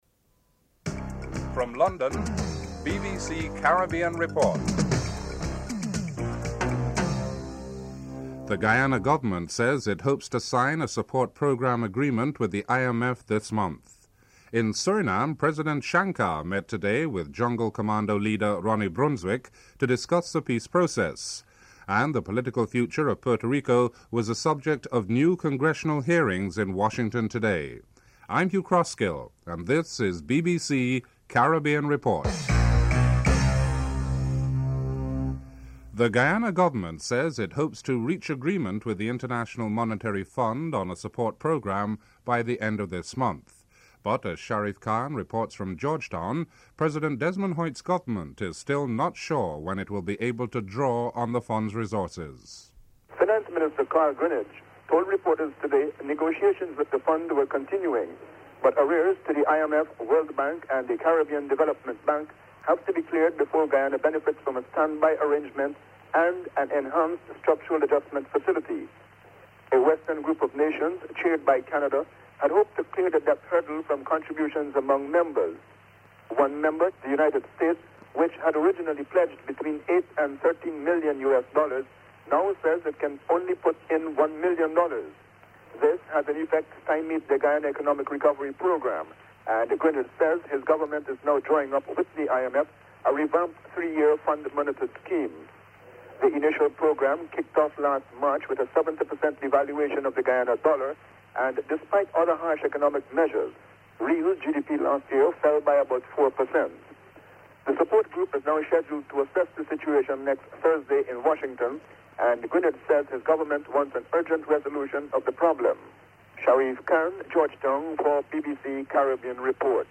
Includes musical interludes at the beginning and end of the report.
1. Headlines (00:00-01:27)
7. Sporting segment. Christopher Martin Jenkins reports on the unexpected win of the English cricket team against the West Indies (13:17-15:41)